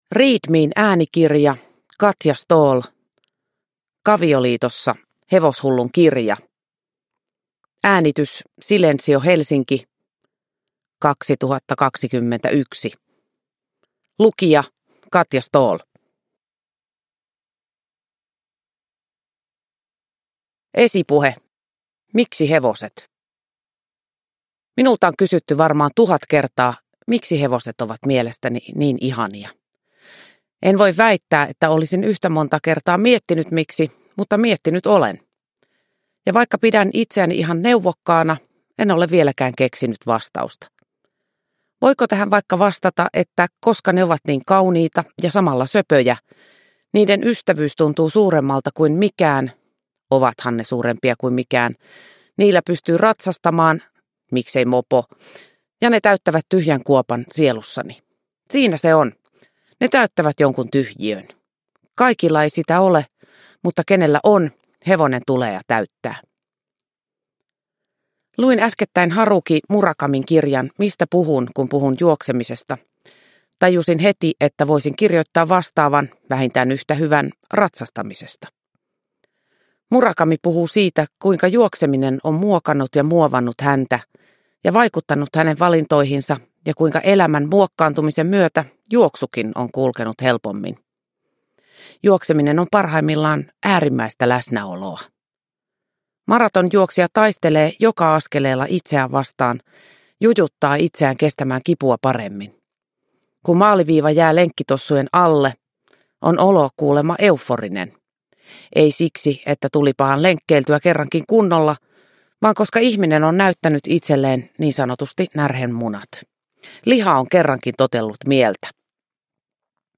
Kavioliitossa (ljudbok) av Katja Ståhl